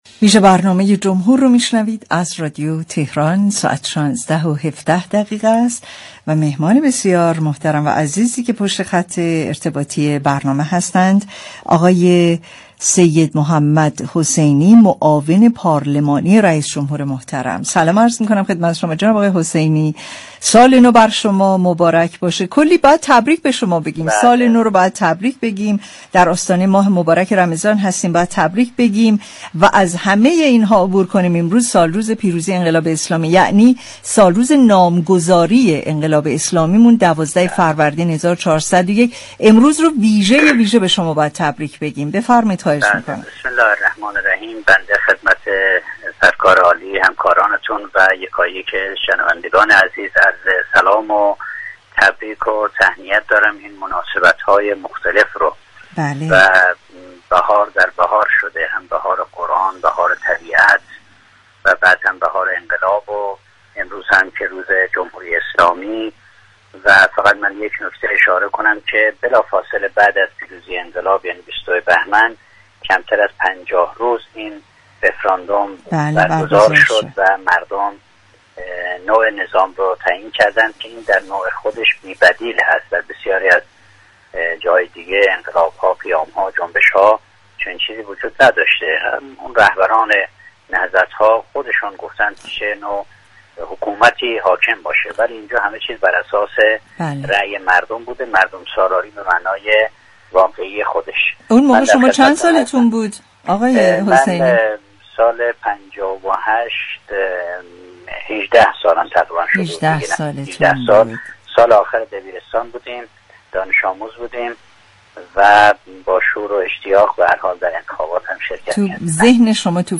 به گزارش پایگاه اطلاع رسانی رادیو تهران، سید محمد حسینی معاون پارلمانی رئیس‌جمهوری در گفتگو با ویژه‌برنامه "جمهور" به مناسبت نامگذاری 12 فروردین به عنوان روز جمهوری اسلامی گفت: بلافاصله پس از پیروزی انقلاب اسلامی در 22 بهمن‌ماه سال 57 و كمتر از 50 روز، در روز 12 فروردین‌ماه رفراندوم برگزار شد و در این ‌همه‌پرسی مردم نوع نظام را تعیین كردند و از آن‌ پس این روز با عنوان روز جمهوری اسلامی ایران نام‌گذاری شد.